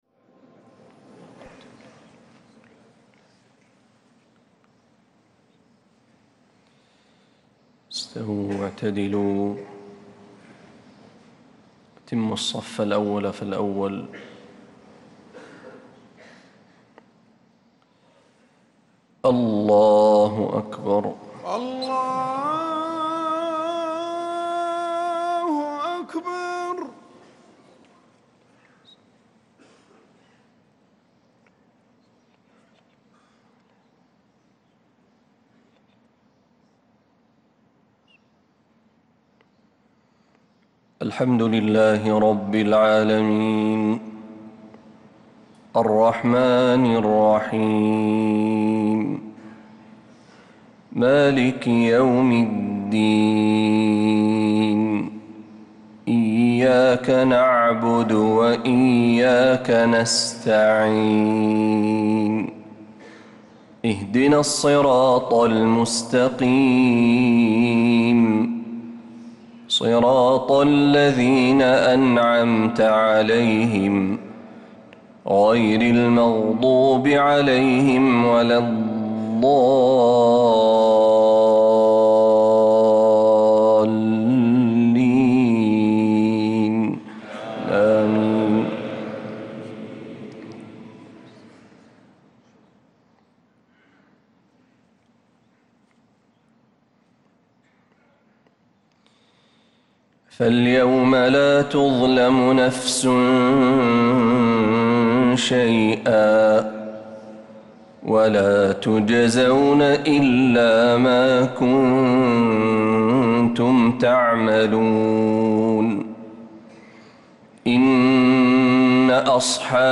صلاة الفجر للقارئ محمد برهجي 30 ربيع الآخر 1446 هـ